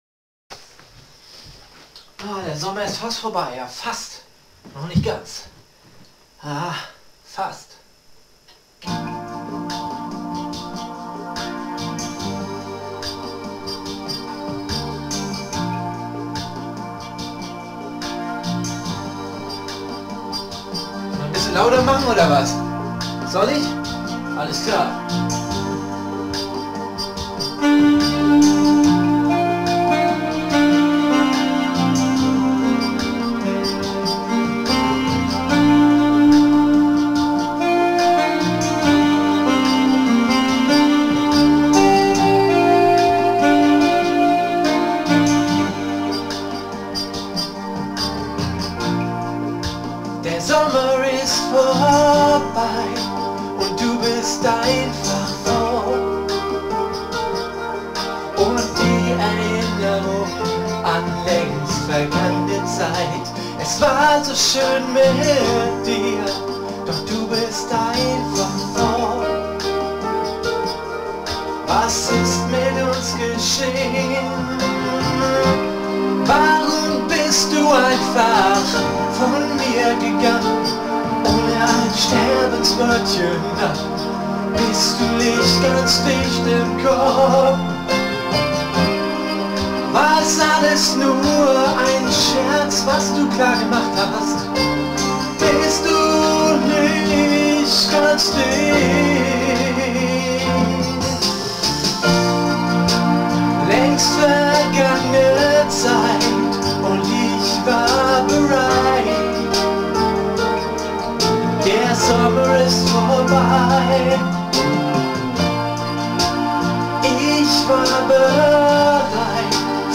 Live 2007